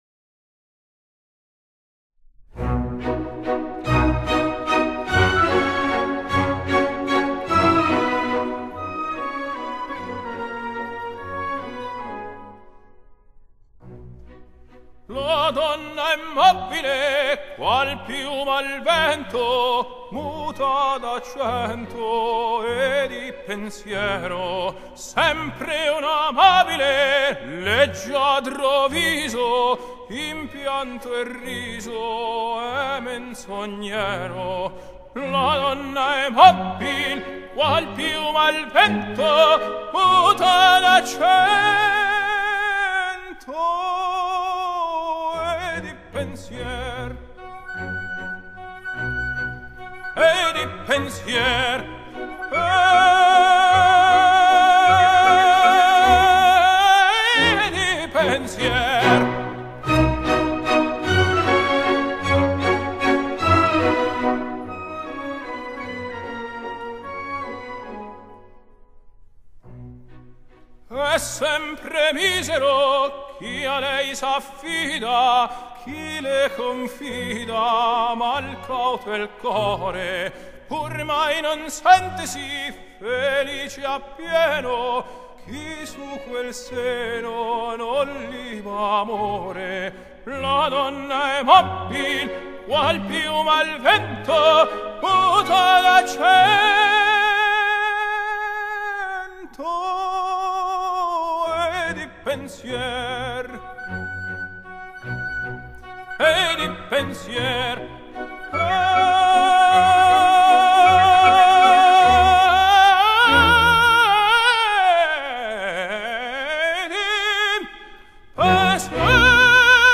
Genre: Classical, Opera